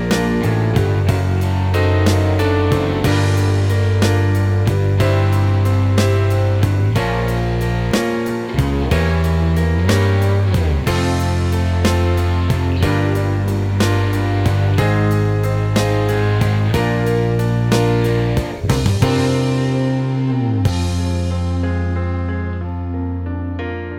Minus Guitars Rock 3:07 Buy £1.50